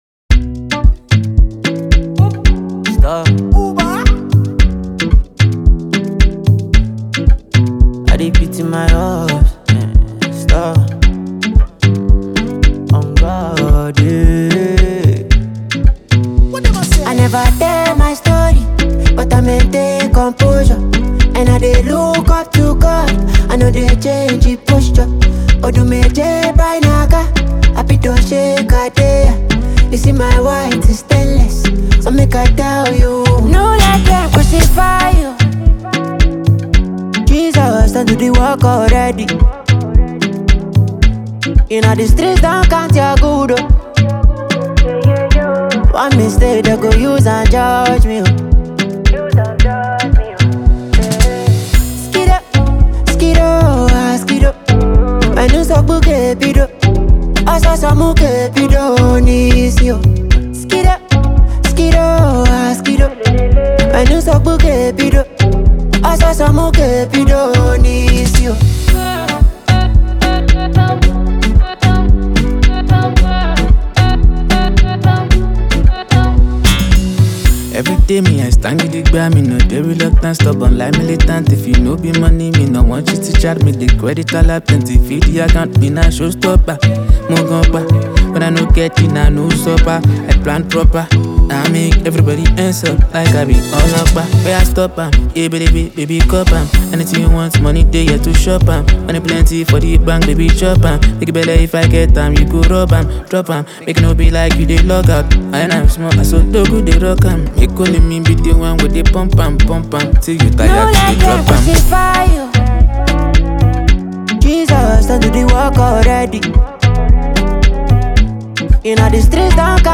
legendary rapper
infectious groove, catchy chorus, and vibrant rhythm